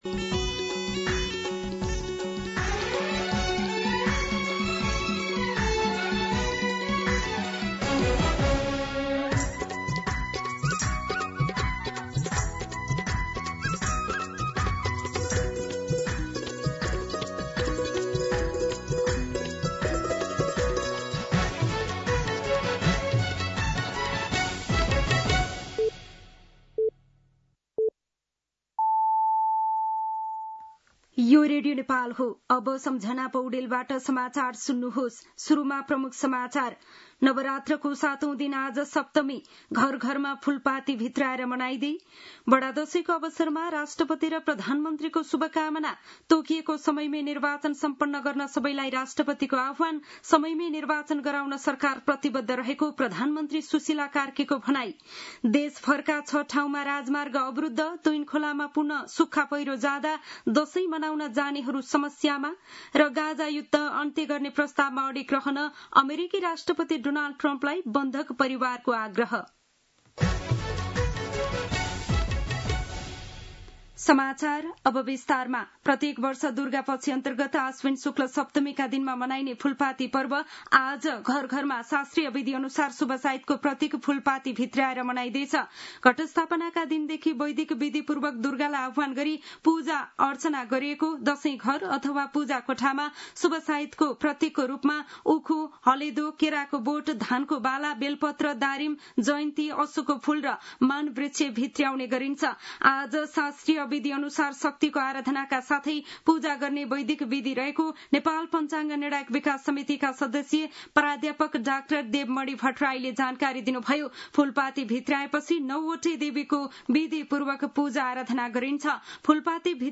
दिउँसो ३ बजेको नेपाली समाचार : १३ असोज , २०८२
3-pm-News-2.mp3